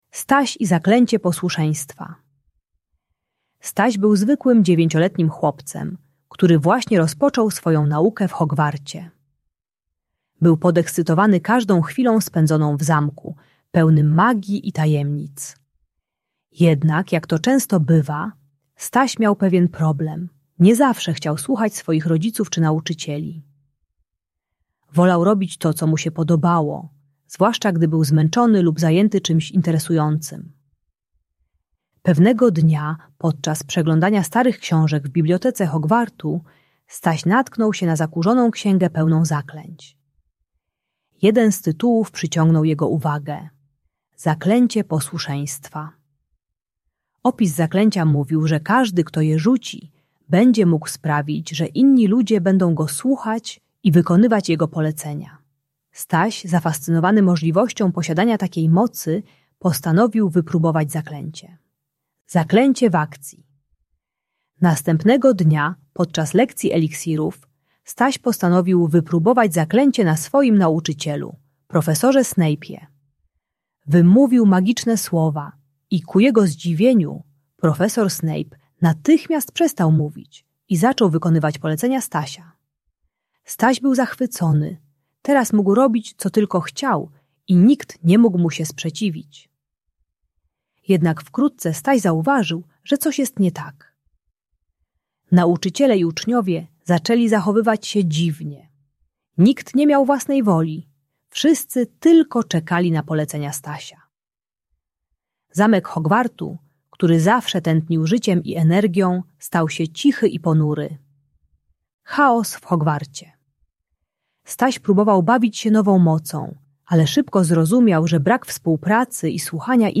Zaklęcie Posłuszeństwa - Bunt i wybuchy złości | Audiobajka